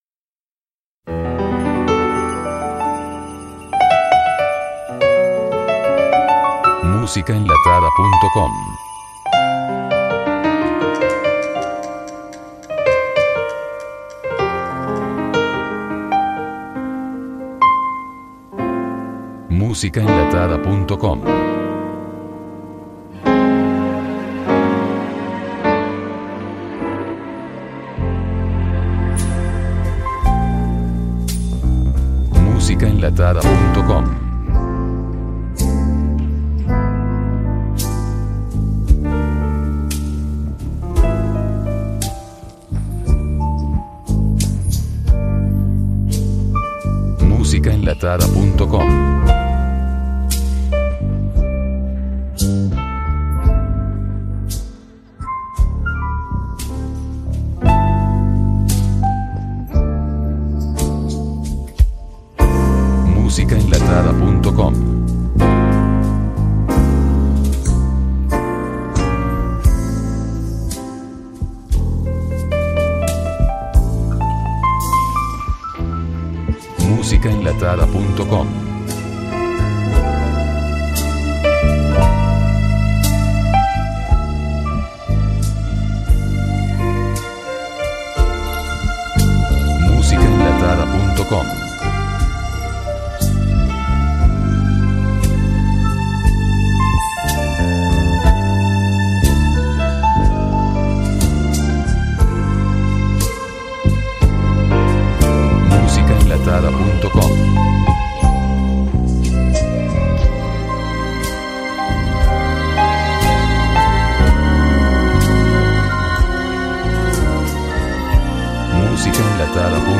Pista Instrumental Disponible: